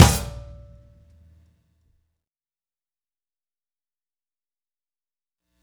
Indie Pop Beat Ending 02.wav